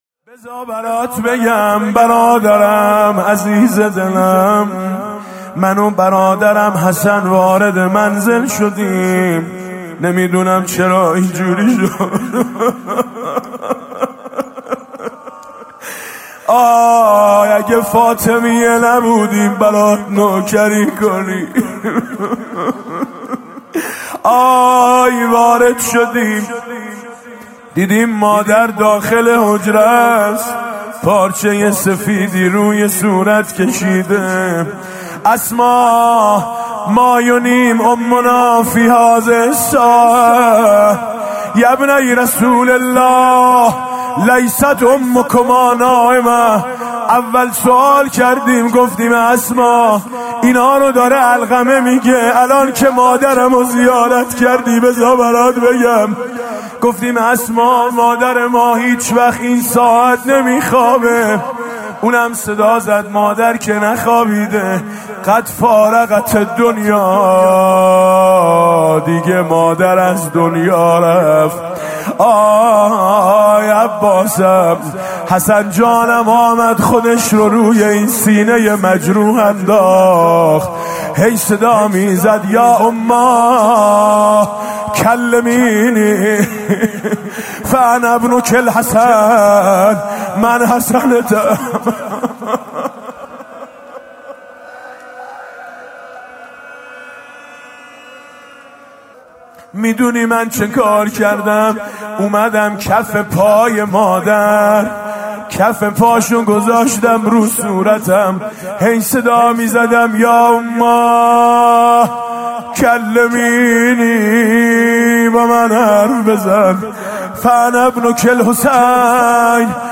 محرم 99